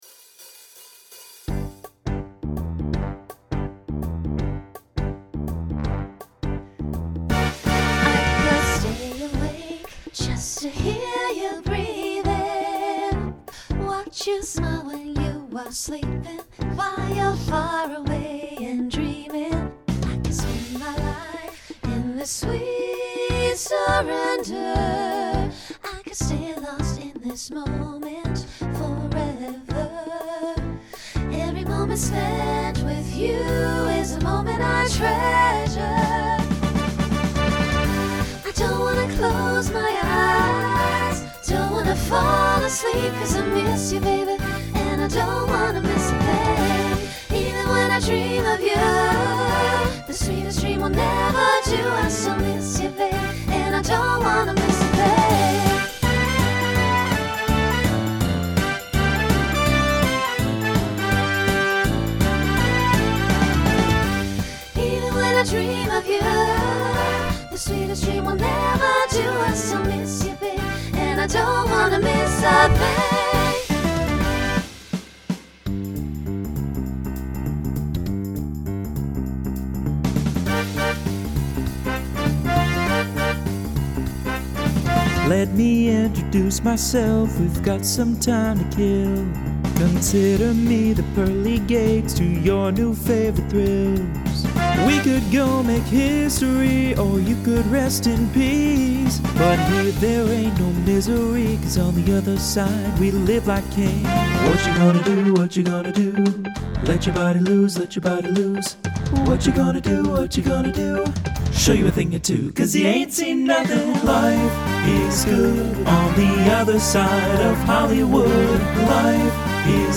SSA
TTB-SATB
Genre Pop/Dance , Swing/Jazz
Voicing Mixed